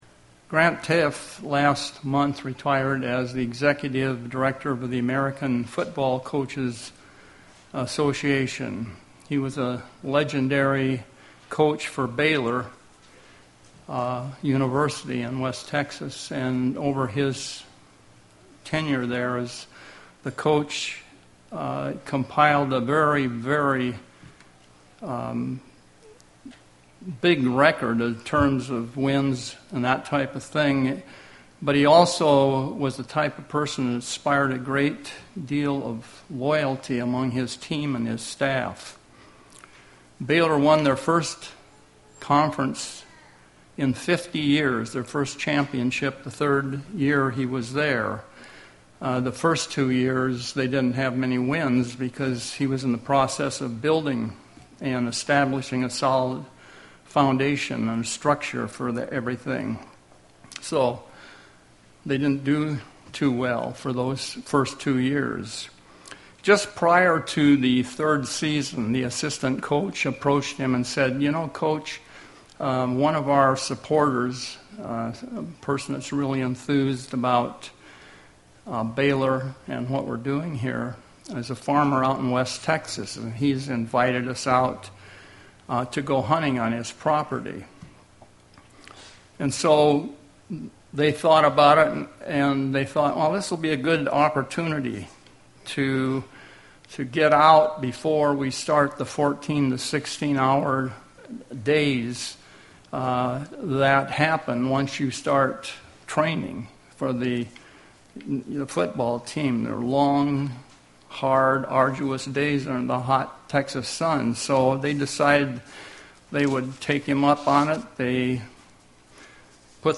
Given in Seattle, WA
Print UCG Sermon